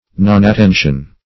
\Non`at*ten"tion\